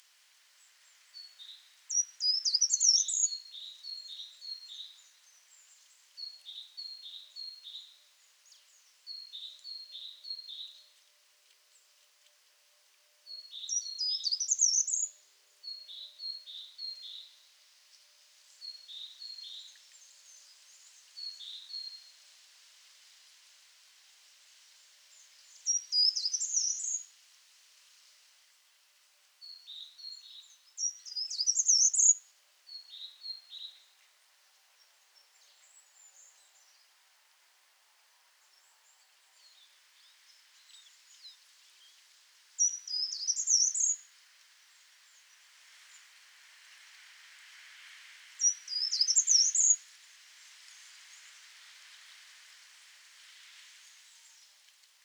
• (Certhia brachydactyla)
Rampichino comune...
Rampichino-comune.mp3